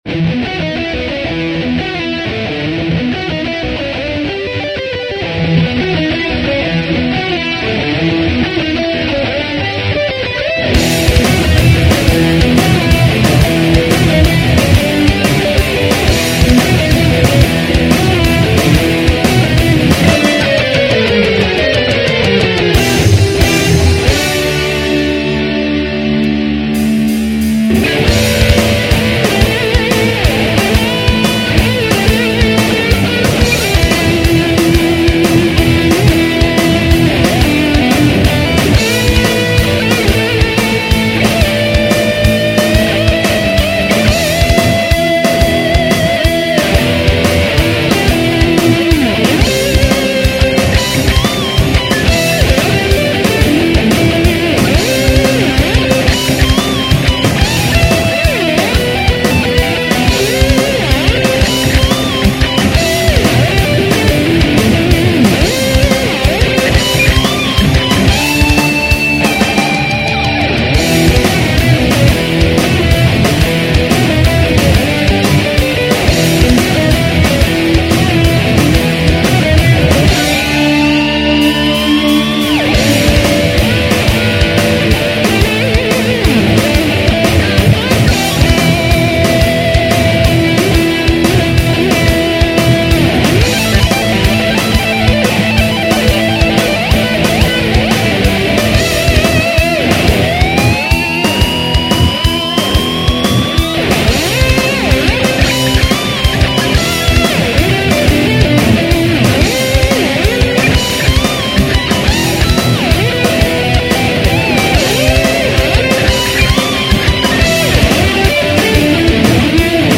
tres temas instrumentales
Guitarra Electrica